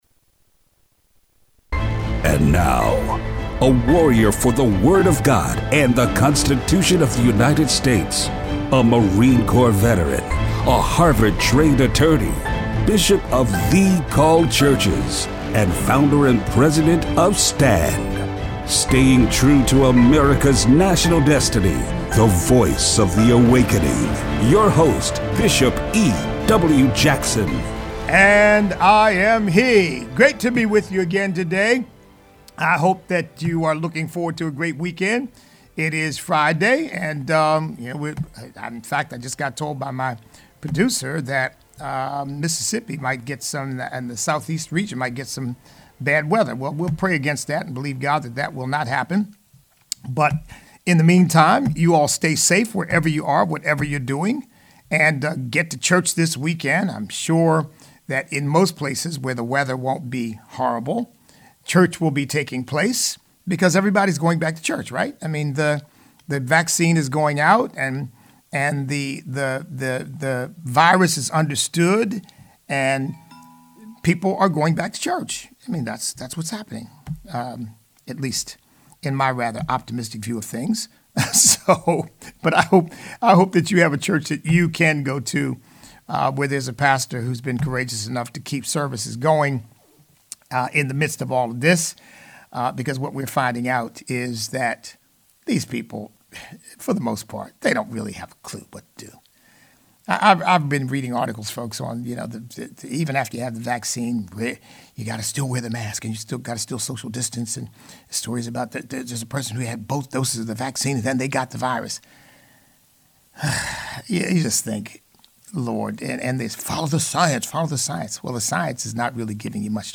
Show Notes Open phone lines!